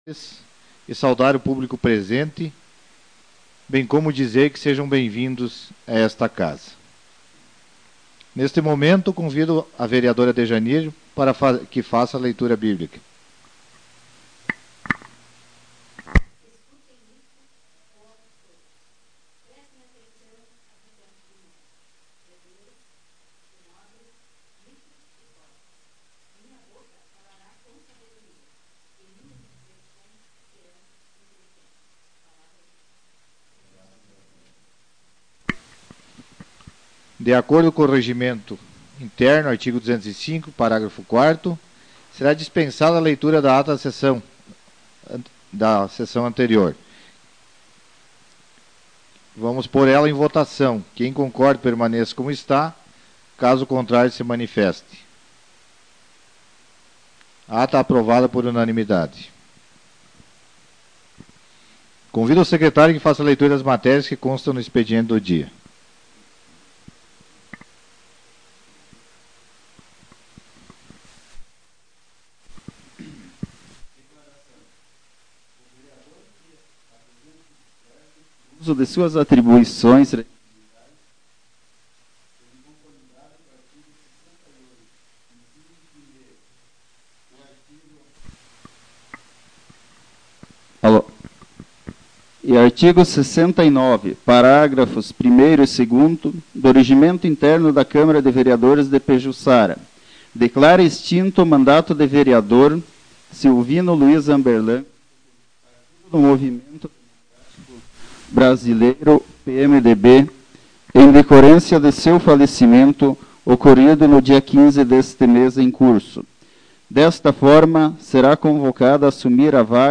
Sessão Ordinária de 2009